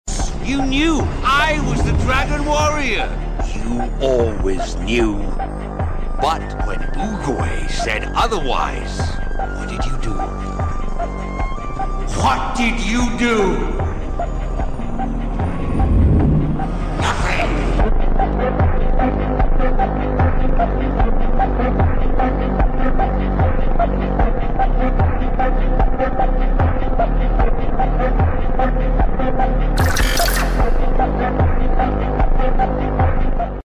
Super Slowed &Reverb